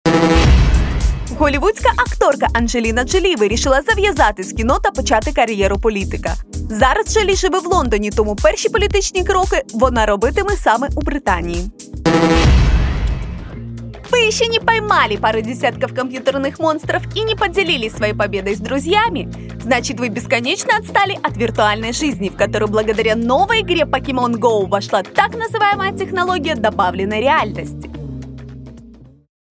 Візитка